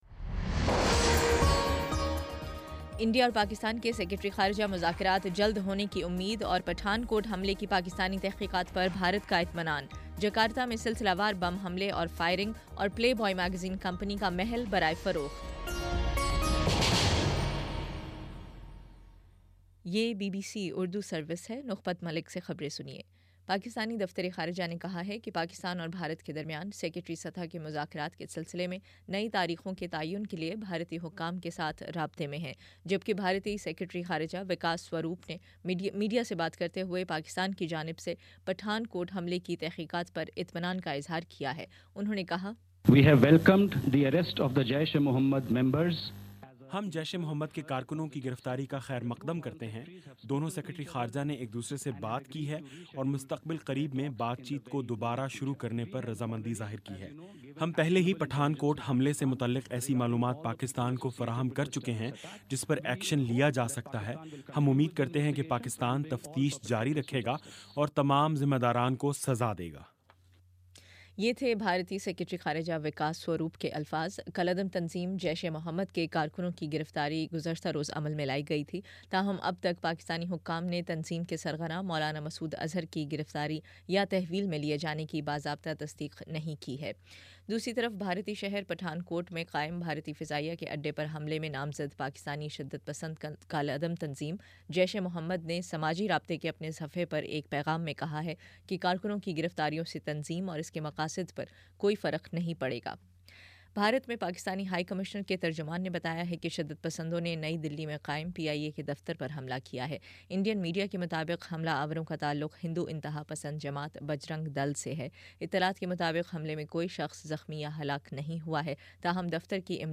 جنوری 14 : شام پانچ بجے کا نیوز بُلیٹن